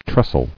[tres·tle]